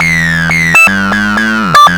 BASS LOOPS - PAGE 1 2 4 5